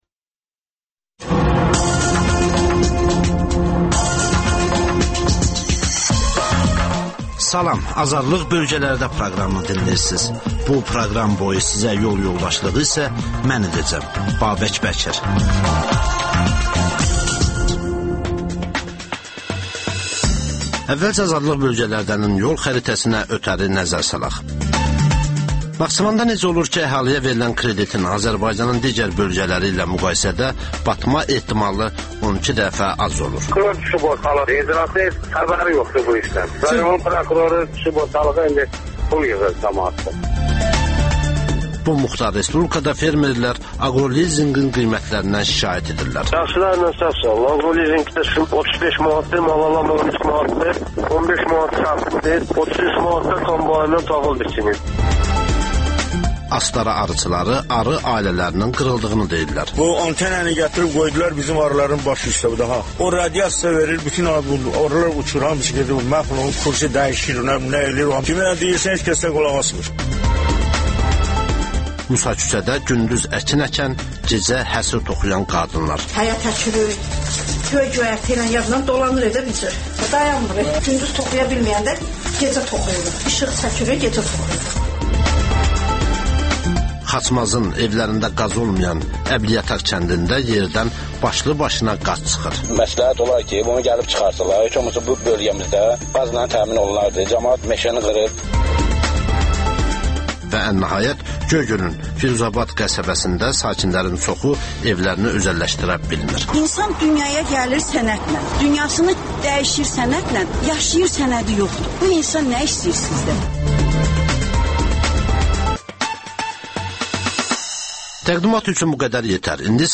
Rayonlardan xüsusi reportajlar.